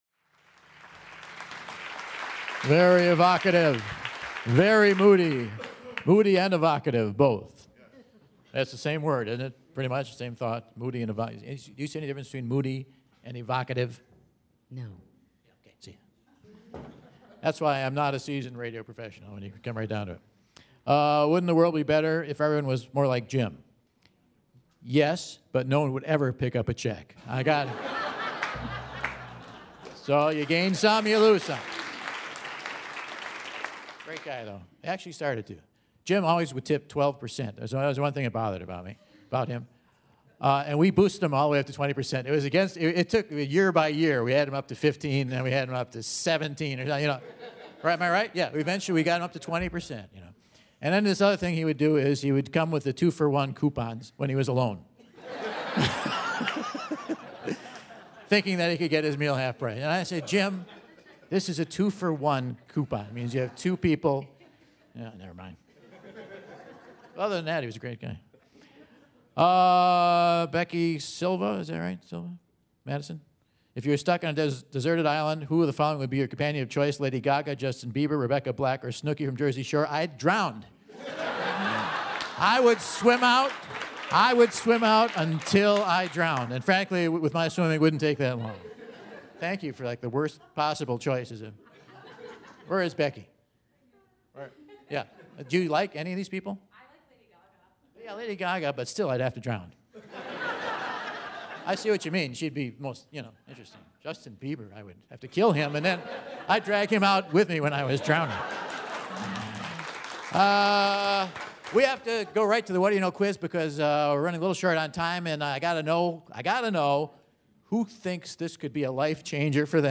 Two more contestants are along for the ride during another edition of the Whad'Ya Know? Quiz!